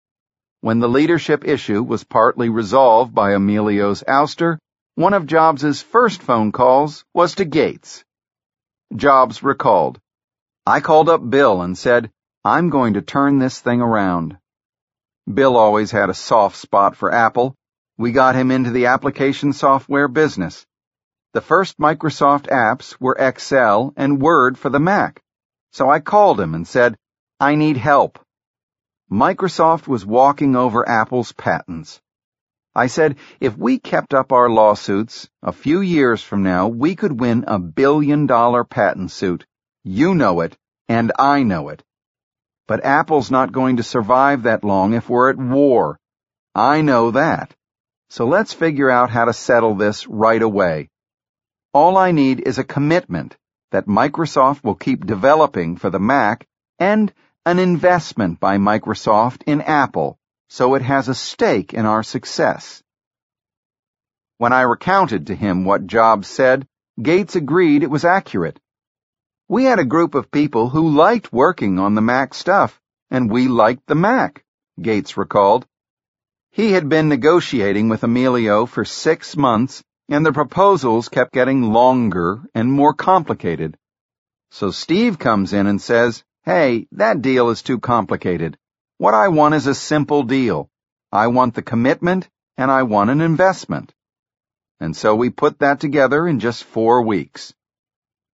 在线英语听力室乔布斯传 第400期:微软契约(3)的听力文件下载,《乔布斯传》双语有声读物栏目，通过英语音频MP3和中英双语字幕，来帮助英语学习者提高英语听说能力。
本栏目纯正的英语发音，以及完整的传记内容，详细描述了乔布斯的一生，是学习英语的必备材料。